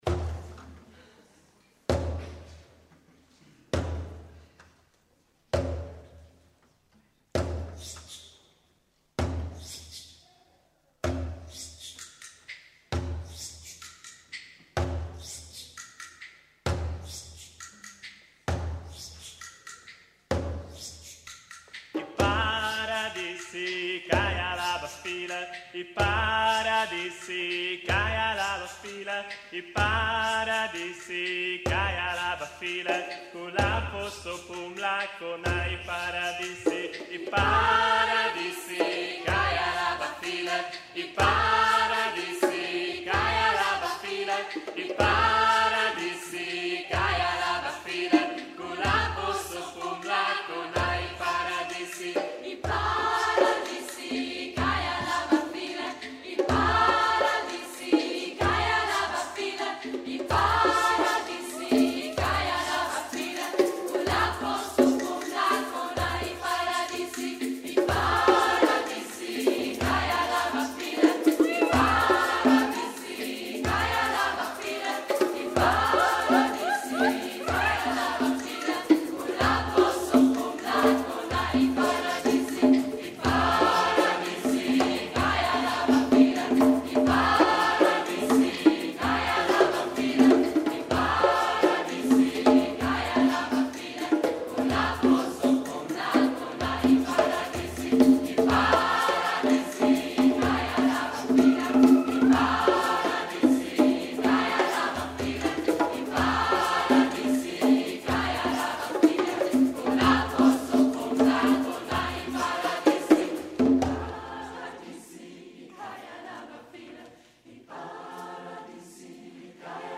Chorauftritt in Ungarn
November 2010 (SE): Wie bereits weiter unten erwähnt, waren wir (Gospelchor „GoCho Frieden und Hoffnung“) zu Besuch bei unserem Partnerchor in Pècs, Ungarn. Nun gibt es auch die Liveaufnahme unseres Konzertes im Internet.